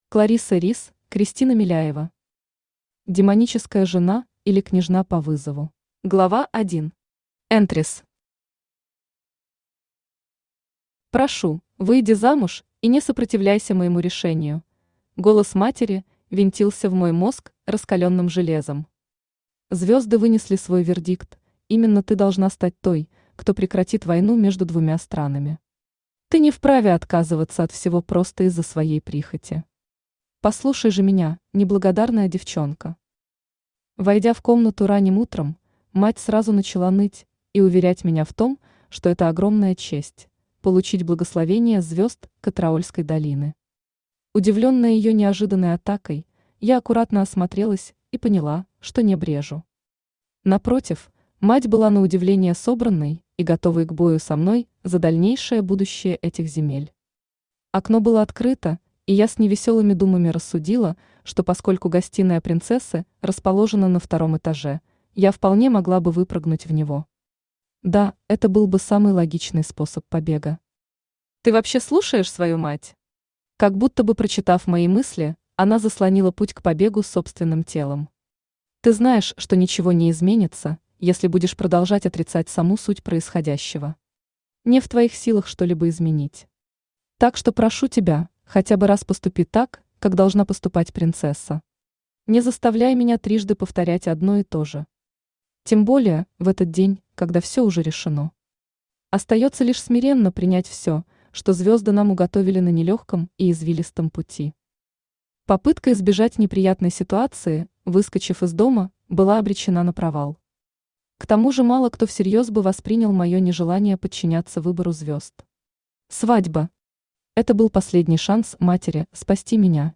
Аудиокнига Демоническая жена, или Княжна по вызову | Библиотека аудиокниг
Aудиокнига Демоническая жена, или Княжна по вызову Автор Кларисса Рис Читает аудиокнигу Авточтец ЛитРес.